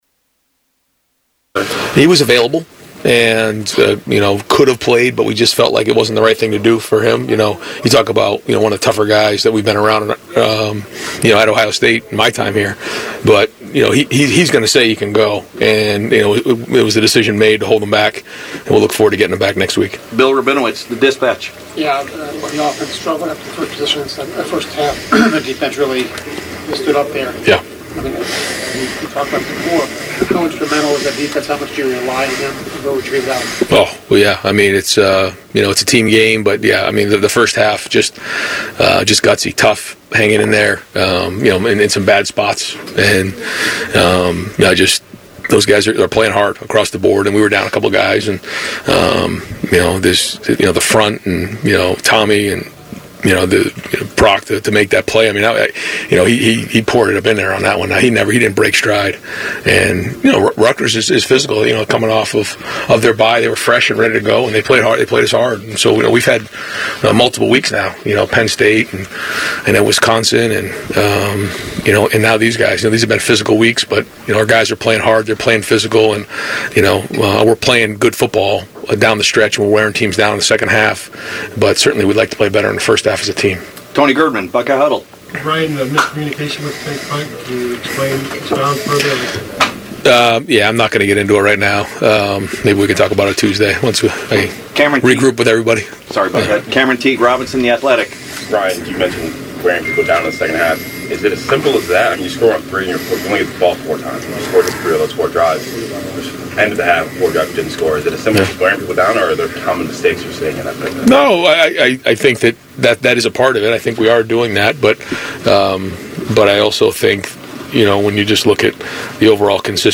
Coach Day starts his press conference off by addressing why TE Cade Stover did play against Rutgers